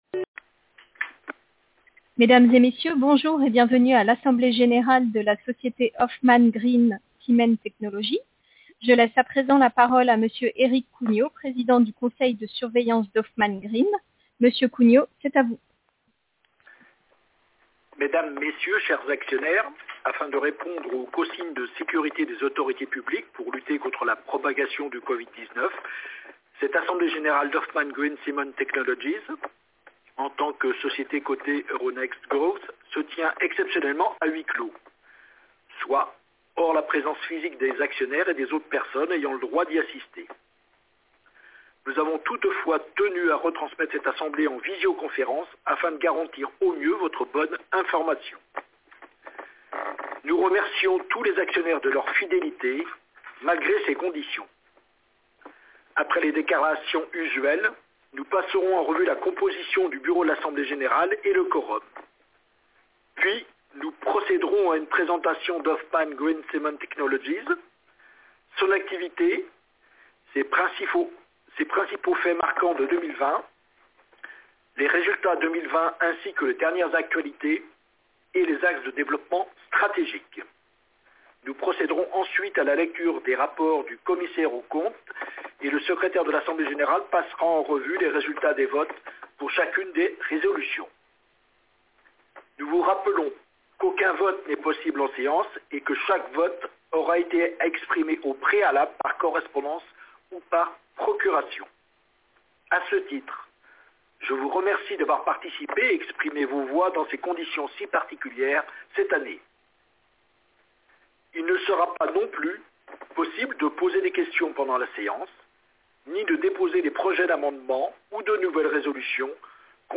Retransmission audio de l’Assemblée Générale Mixte du 4 juin 2021